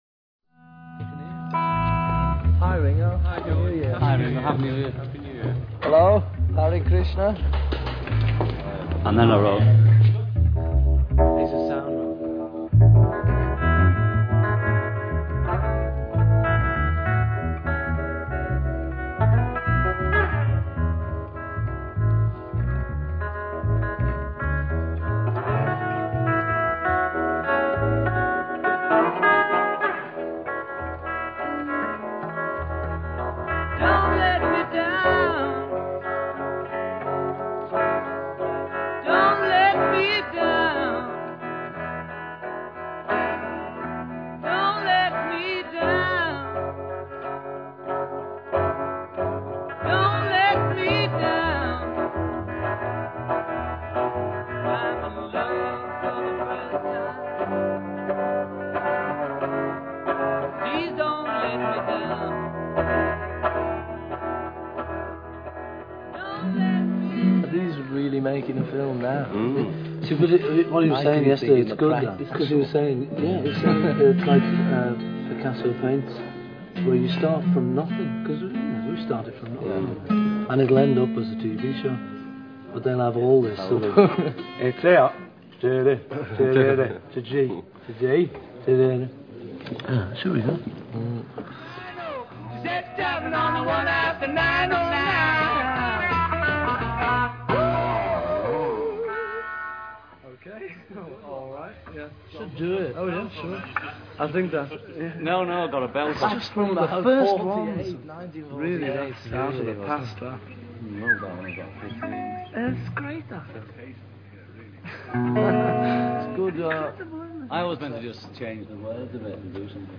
西洋音樂
基本上第二張只能算是錄音間裡頭收集的錄音，收音效果並不是很好，且雜音甚多。